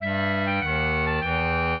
minuet9-2.wav